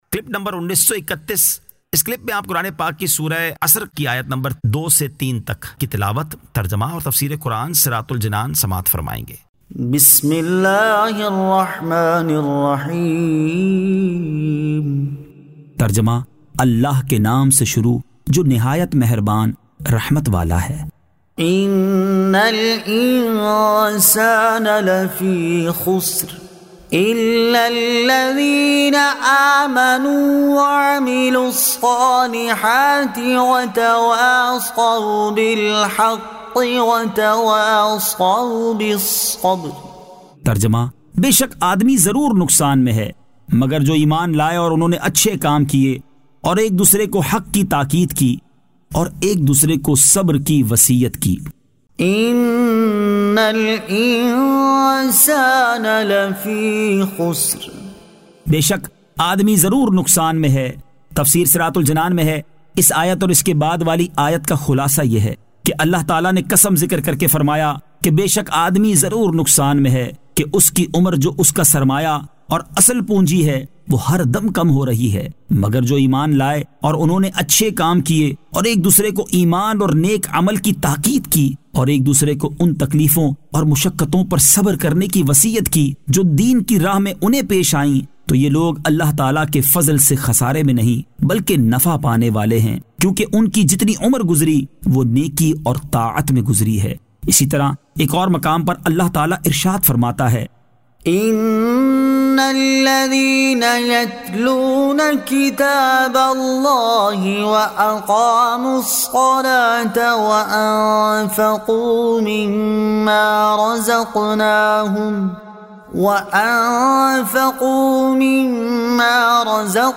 Surah Al-Asr 02 To 03 Tilawat , Tarjama , Tafseer